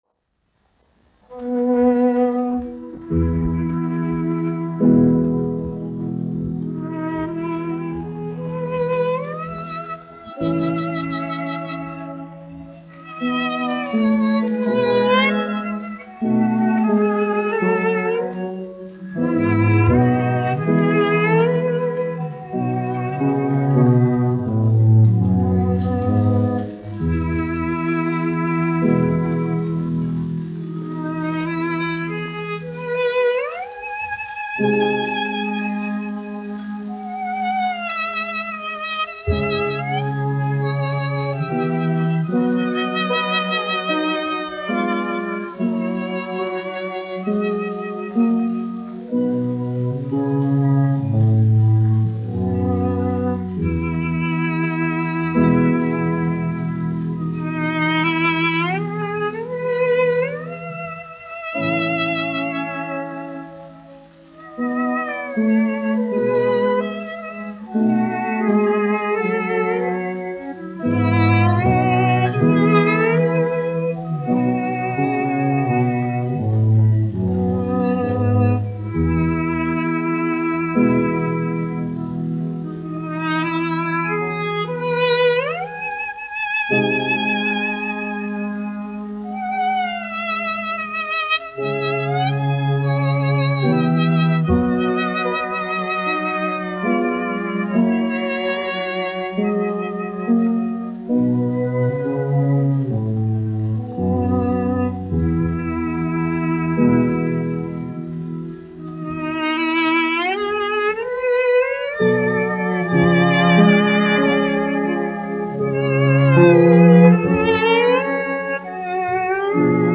1 skpl. : analogs, 78 apgr/min, mono ; 25 cm
Kamermūzika
Skaņuplate
Latvijas vēsturiskie šellaka skaņuplašu ieraksti (Kolekcija)